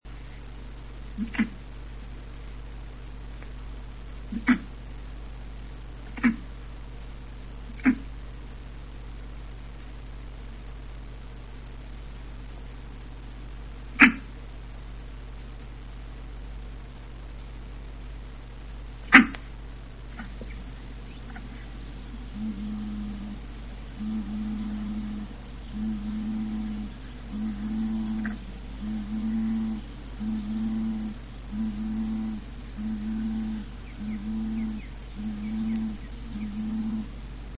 zmrocznik sowik (paszczak australijski) - Podargus strigoides - największy z lelkowych, mieszkaniec głównie lasów deszczowychmp3146 kb